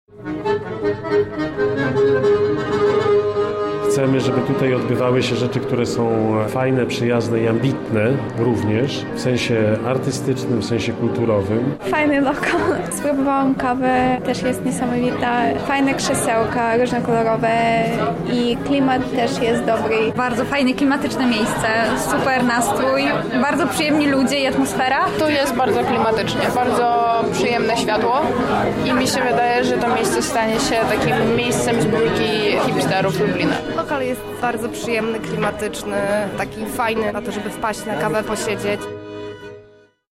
Otwarcie lokalu zrealizowane zostało w ramach obchodów 700-lecia miasta Lublina.